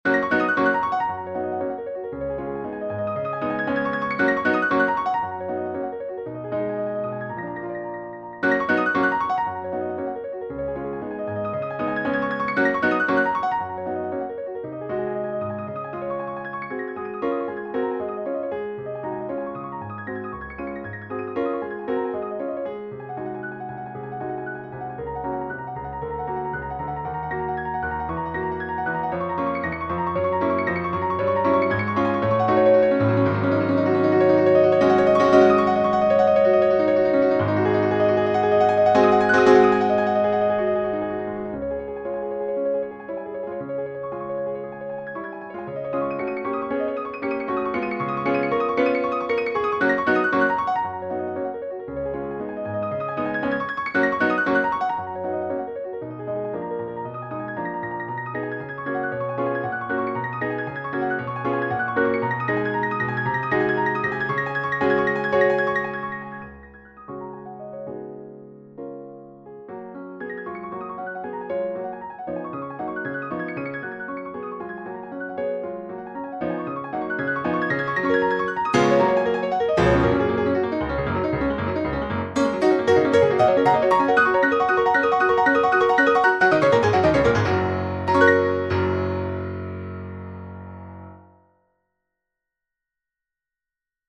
• 右手は黒鍵のみ: 右手は黒鍵のみを演奏し、滑らかで軽快な動きが求められます。
• 左手の伴奏: 左手は跳躍を伴う伴奏を演奏し、右手の旋律を支えます。
• 華やかで技巧的な作品: 華やかで技巧的な作品であり、高度な演奏技術が必要です。
• A-B-Aの三部形式: A-B-Aの三部形式で構成されています。
• コーダ: コーダでは、右手の技巧的なパッセージが再び現れ、華やかに曲を締めくくります。